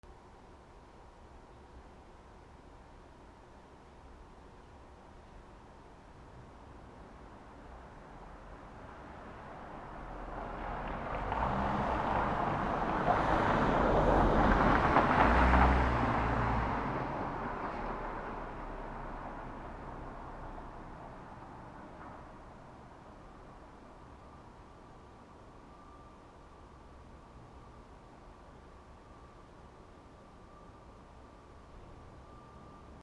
車通過
/ C｜環境音(人工) / C-57 ｜再構成用_車通過